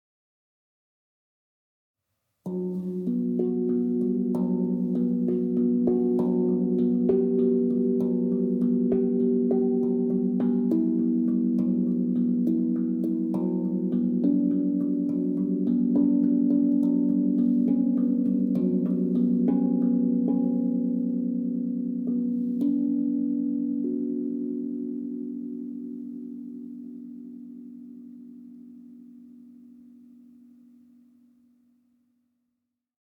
Meinl Sonic Energy 10" Medium Octave Steel Tongue Drum, B Minor, 8 Notes, 432 Hz, Black (MOSTD1BK)